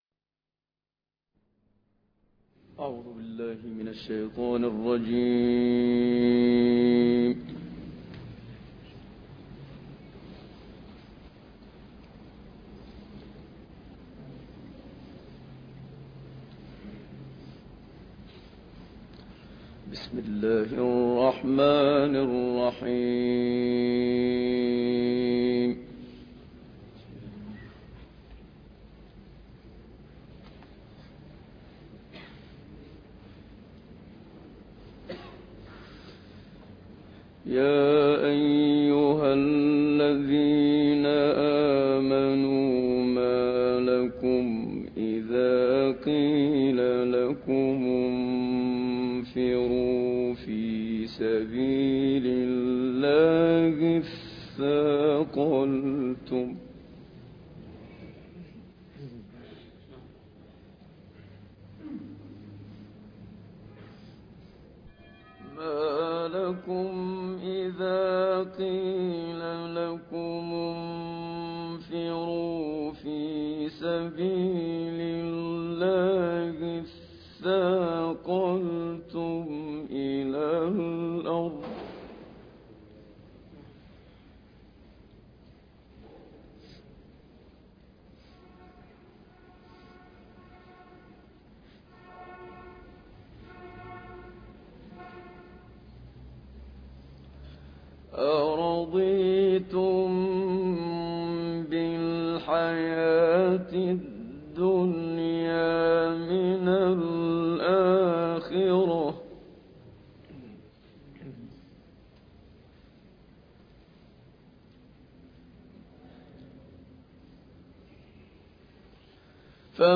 009 التوبة 38-44 تلاوات نادرة بصوت الشيخ محمد صديق المنشاوي - الشيخ أبو إسحاق الحويني